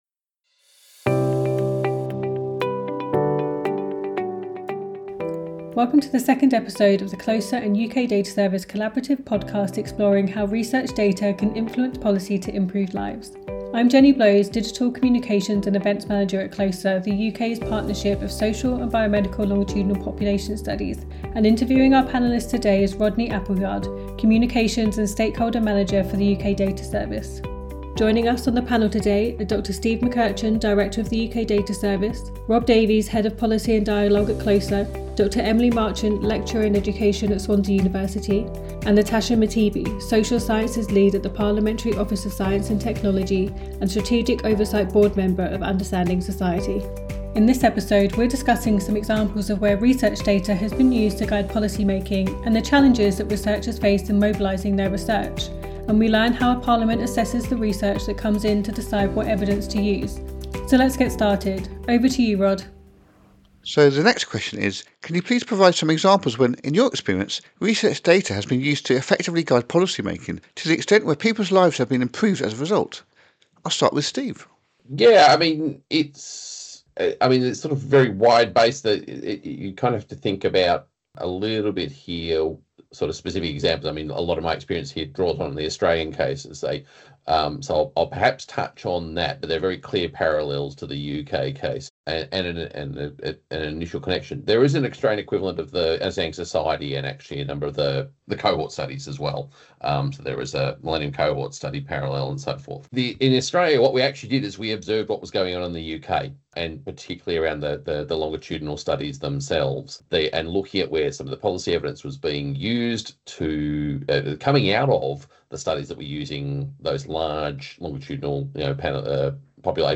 Listen to our new podcast with CLOSER, as we sit down with our expert panel to explore how research data can influence Government policies to improve people's lives.